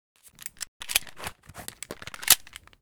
kiparis_reload.ogg